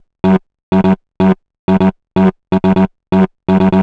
cch_vocal_loop_low_125.wav